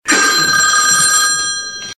OLD TELEPHONE RING EFFECT.mp3
Old Rotary telephone ringing in my grandmother's kitchen.
old_telephone_ring_effect_6mq.ogg